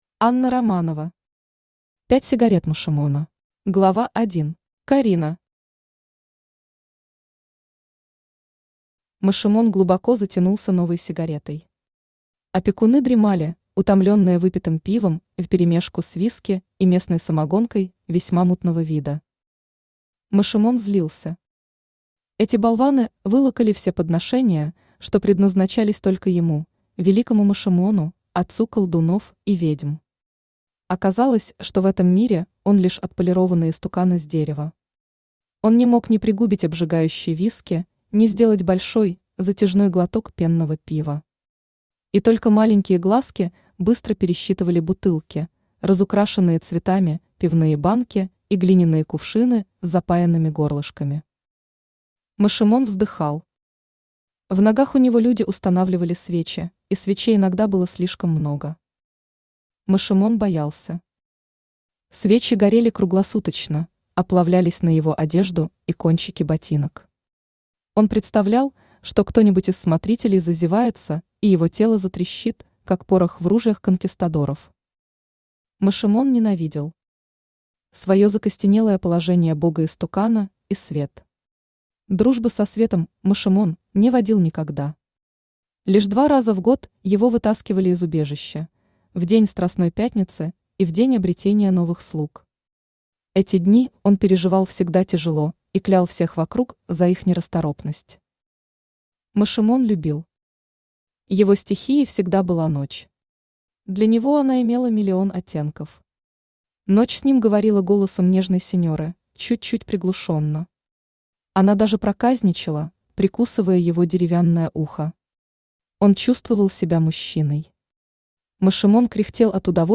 Аудиокнига Пять сигарет Машимона | Библиотека аудиокниг
Aудиокнига Пять сигарет Машимона Автор Анна Романова Читает аудиокнигу Авточтец ЛитРес.